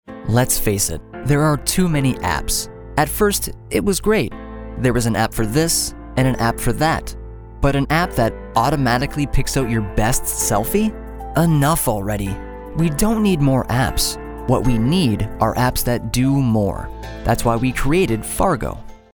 Male
Yng Adult (18-29), Adult (30-50)
Explainer Videos